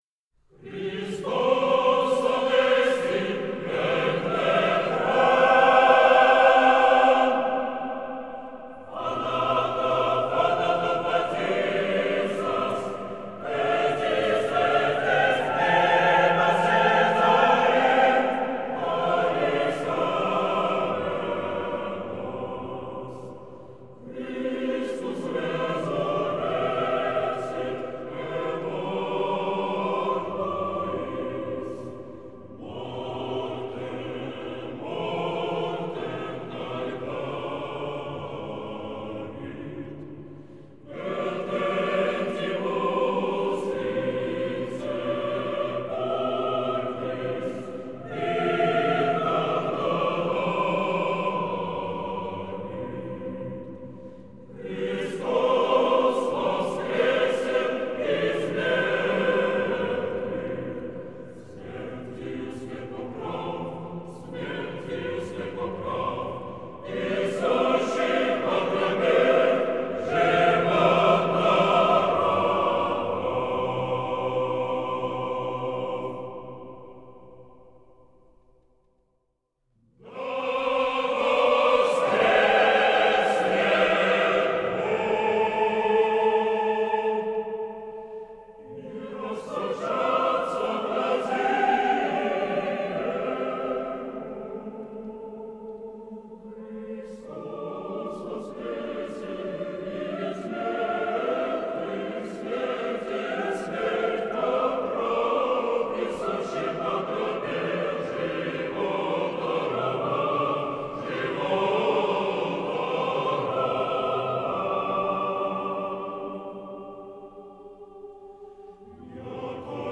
Великий пост - Святая Пасха. Хор